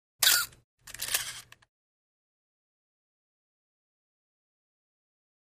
35 mm Still Camera 1; Electronic Shutter Click With Wind.